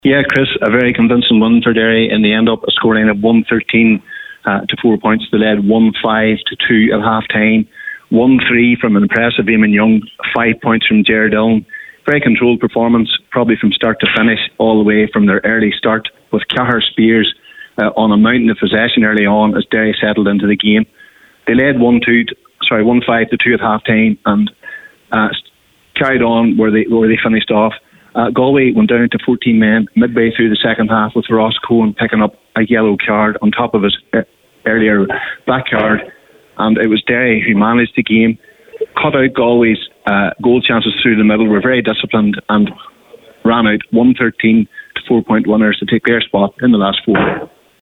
full time report…